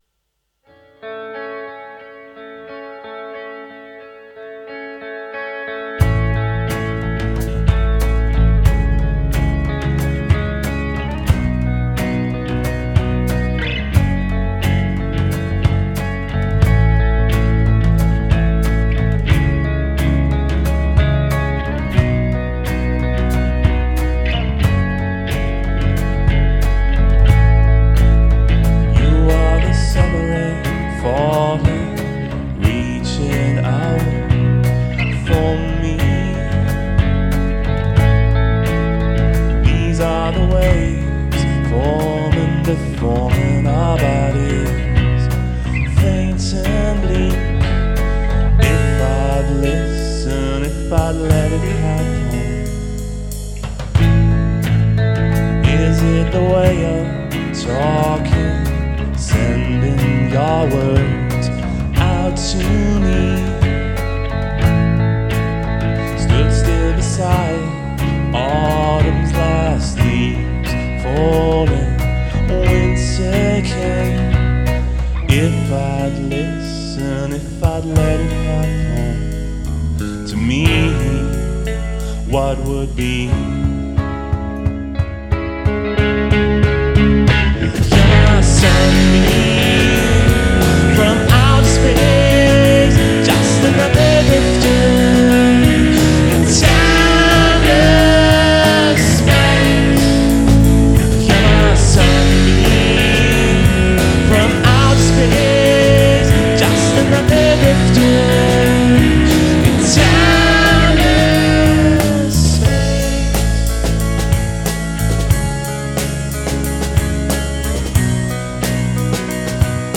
sunbeam_live_recording_bitte _geil_mixen
habe meinem quickmaster jetzt auch noch etwas mehr bleed gegeben
auch wenn meine version wie ein mix klingt - sie ist tatsächlich nur ein remaster deines mixes.
hab fürs mastering ziemlich ungewöhnliche und völlig verrückte sachen ausprobiert...